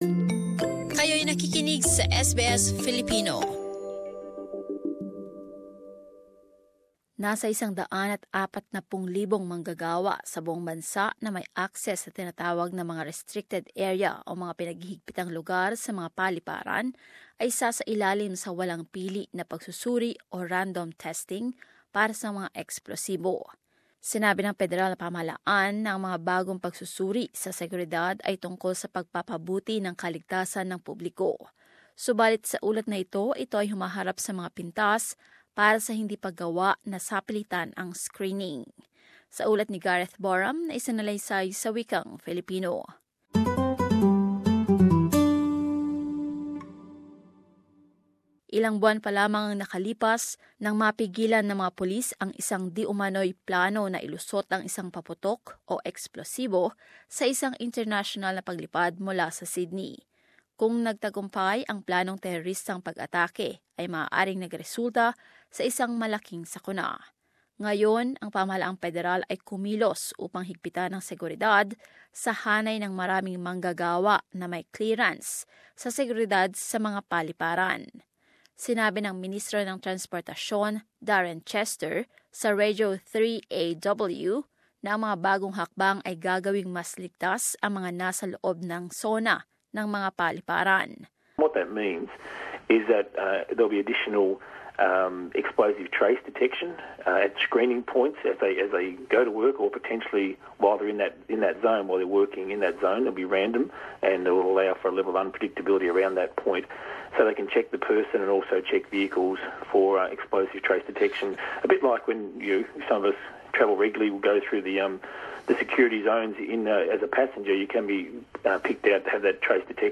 But as this report shows, it has come under criticism for not making the screening mandatory.